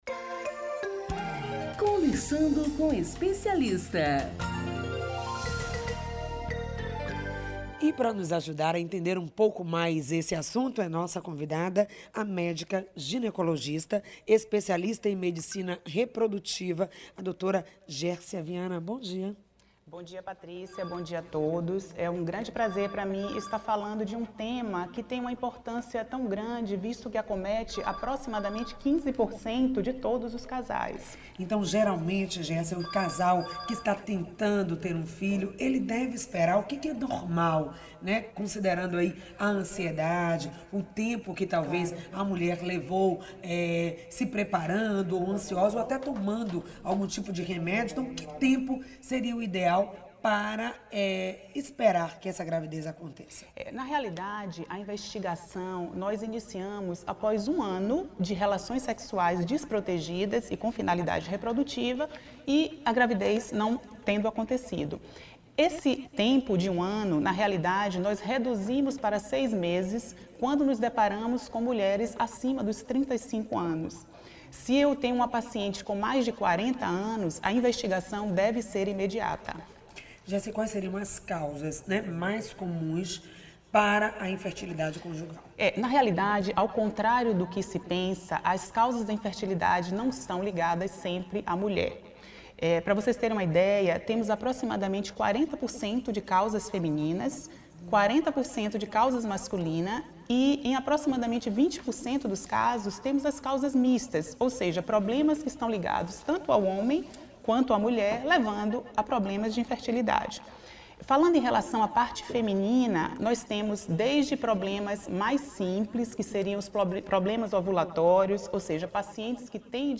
No programa exibido pela Rádio AM 840 (em 24.11.15 das 10 às 11h) são abordados assuntos como: causas da infertilidade em homens e mulheres, diagnóstico e tratamento, mitos e verdades sobre o tema e onde encontrar tratamento mais barato em Salvador.